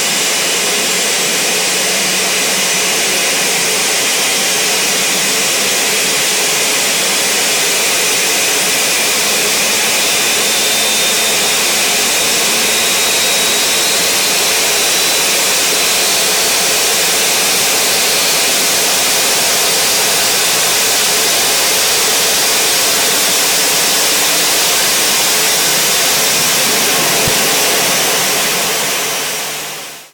CFM56 startup sounds
cfm-startup.wav